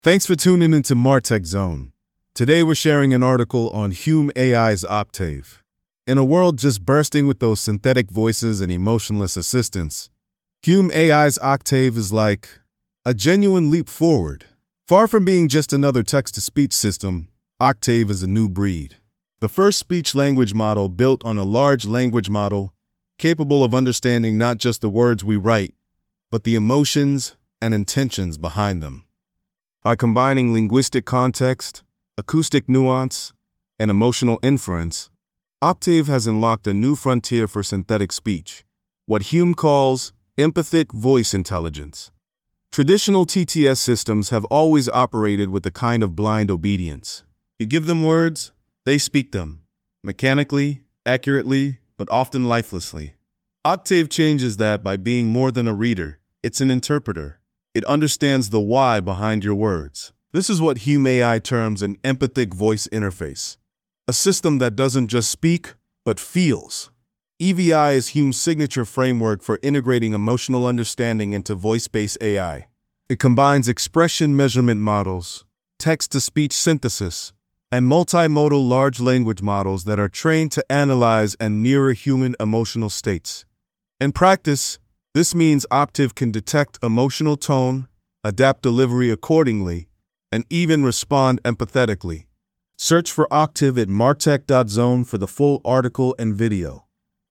Voici une introduction que j’ai créée en quelques minutes à cet article, produit avec Hume AI:
hume-ai-octave-tts.mp3